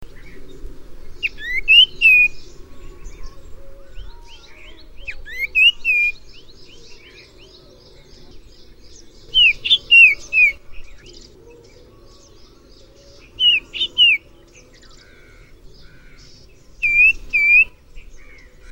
Common Iora
These birds are quite vocal and can produce a wide variety of calls.
• Sound - mixture of churrs and a trilled "wheee-tee" sound. These birds are known to imitate the calls of other species of birds.